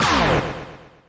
snd_criticalswing_ch1.wav